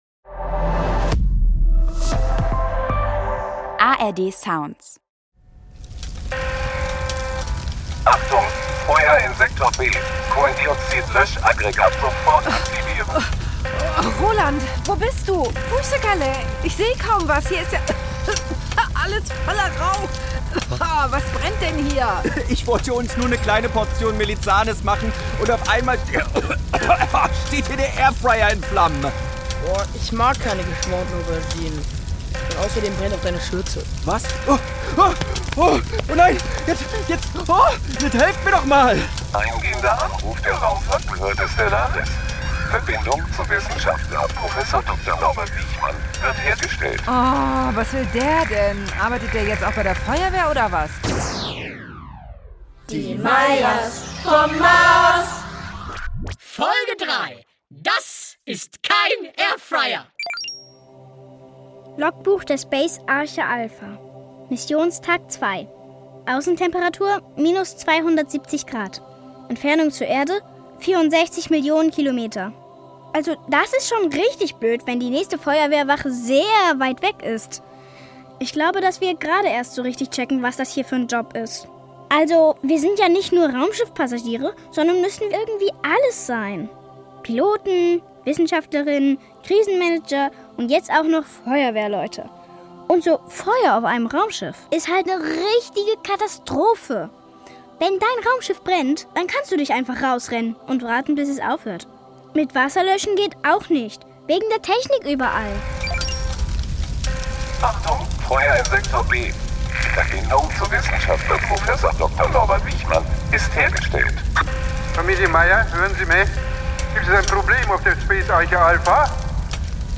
futuristische Familien-Comedy